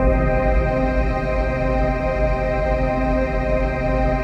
DM PAD2-28.wav